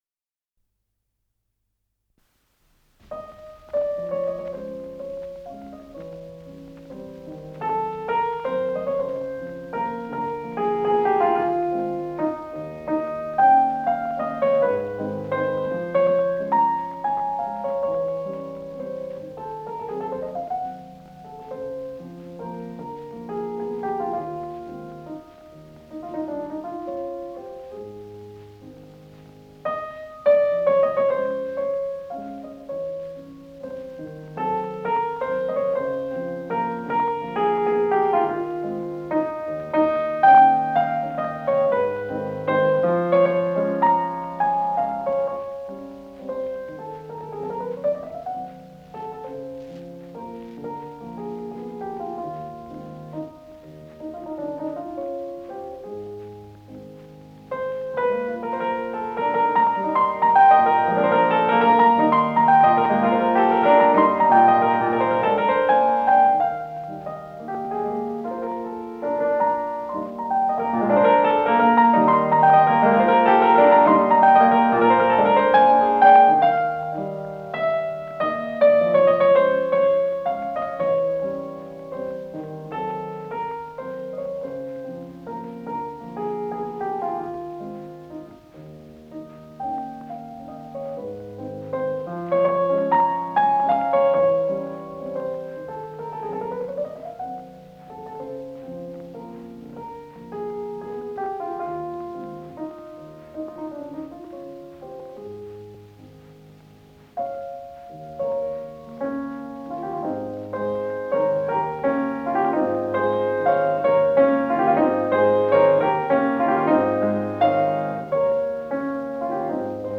с профессиональной магнитной ленты
Подзаголовок"Прощальный вальс", ля бемоль мажор
ИсполнителиАльфред Корто - фортепино
Скорость ленты38 см/с